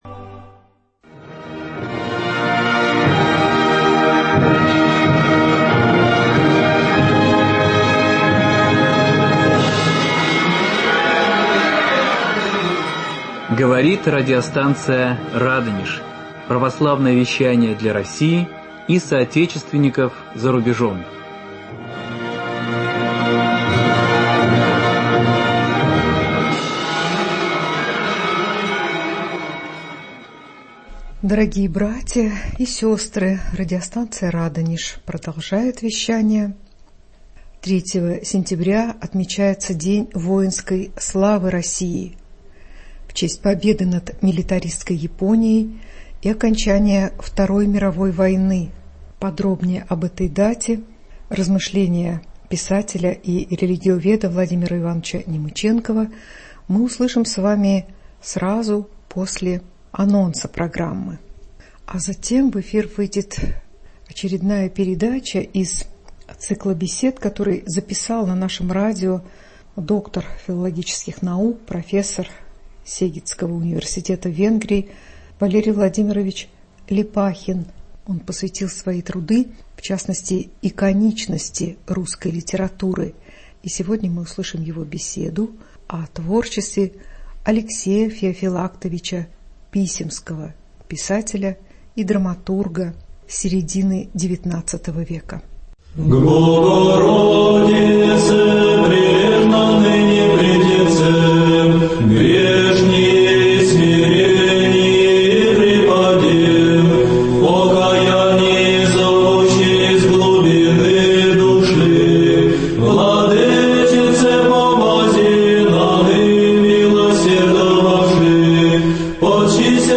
3 сентября - день победы над Японией и окончания Второй мировой войны. Беседа